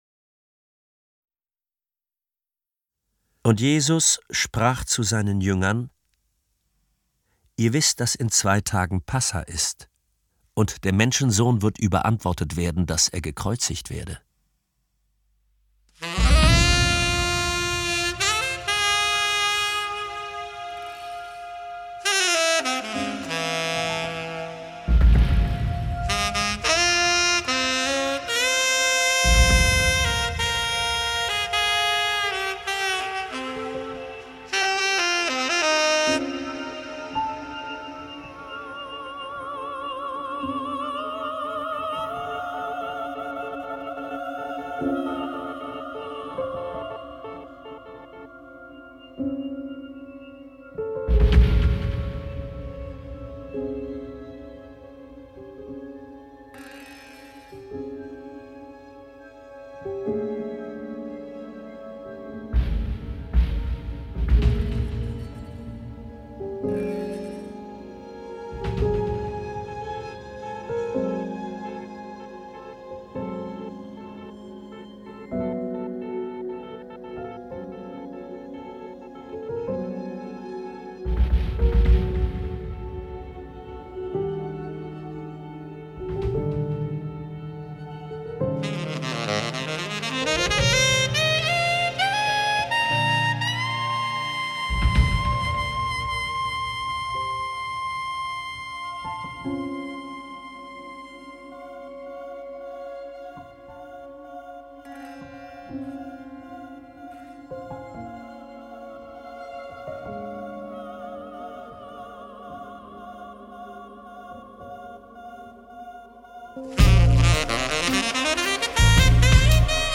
Lesung mit Musik
Wolfram Koch (Sprecher)